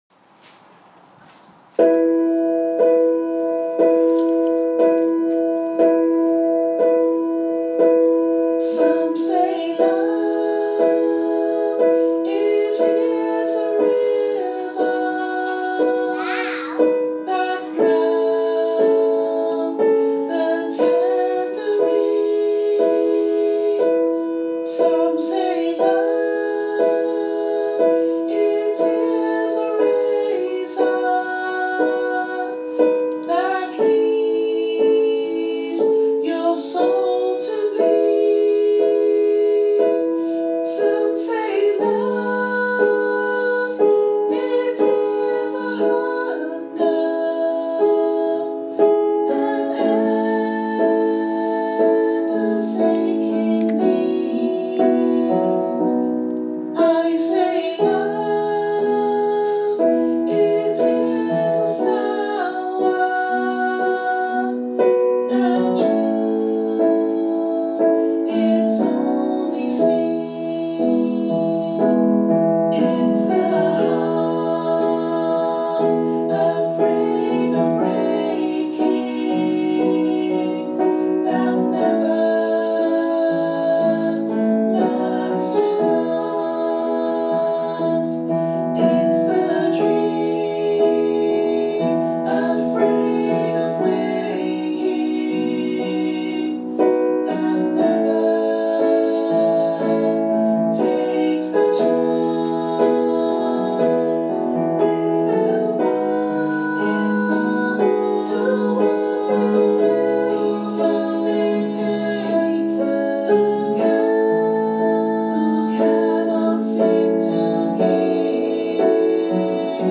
Our Choir's latest challenge